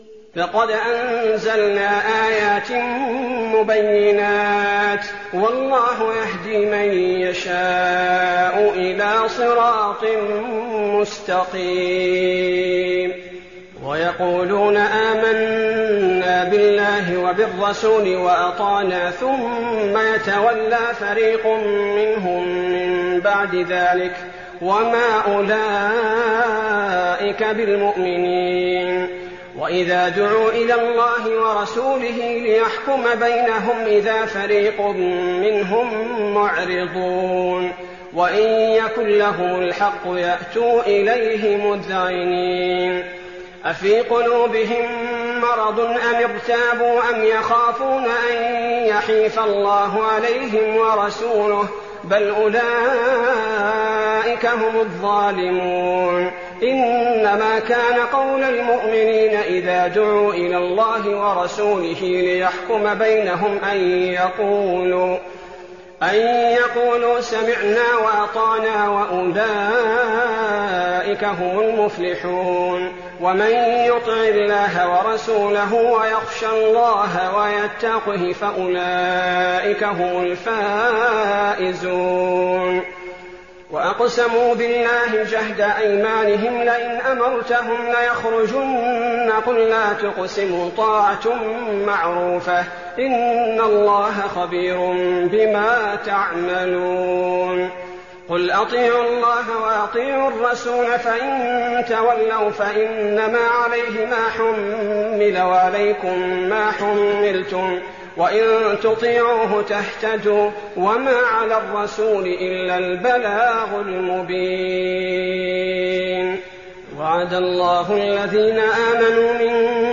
تراويح الليلة السابعة عشر رمضان 1419هـ من سورتي النور (46-64) و الفرقان (1-20) Taraweeh 17th night Ramadan 1419H from Surah An-Noor and Al-Furqaan > تراويح الحرم النبوي عام 1419 🕌 > التراويح - تلاوات الحرمين